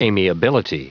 Prononciation du mot amiability en anglais (fichier audio)
Prononciation du mot : amiability